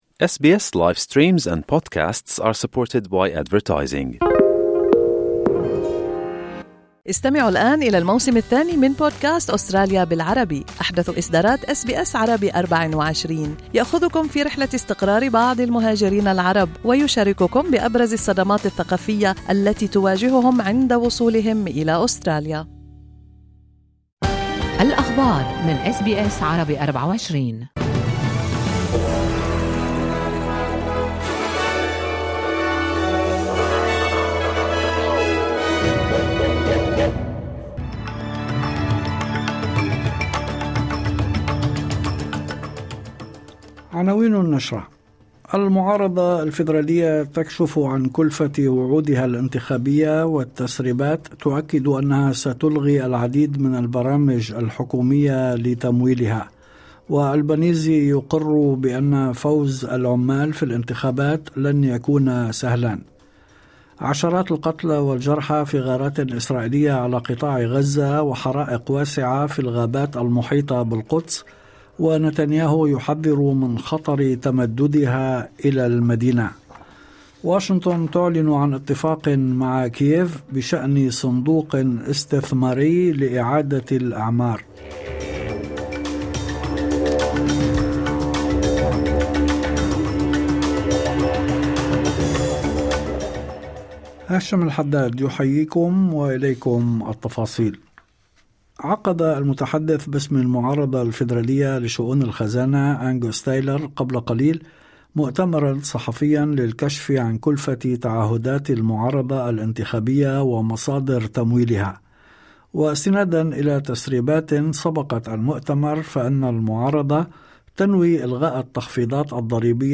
نشرة أخبار المساء 01/05/2025